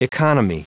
Prononciation du mot economy en anglais (fichier audio)